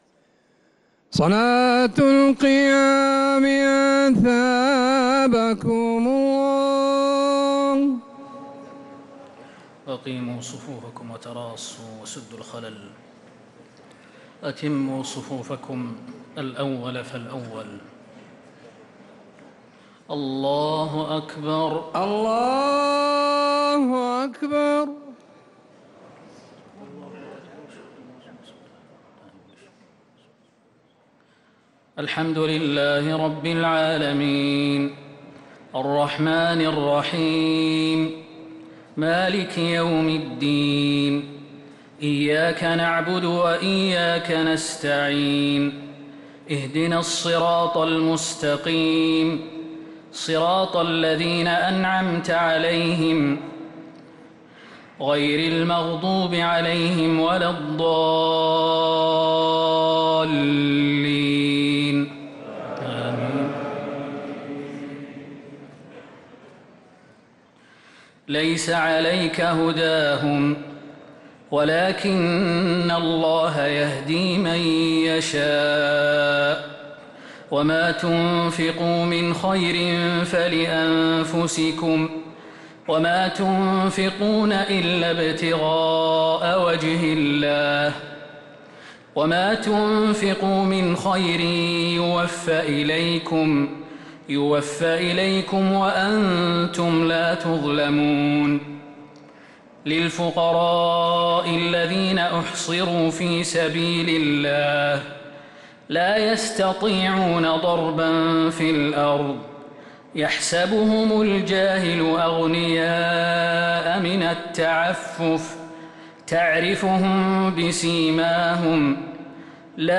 صلاة التراويح ليلة 4 رمضان 1445 للقارئ خالد المهنا - الثلاث التسليمات الأولى صلاة التراويح